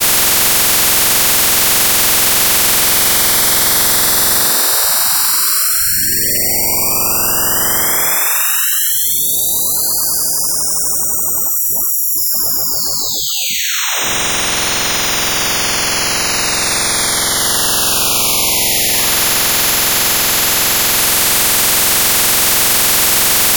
Plot Sonification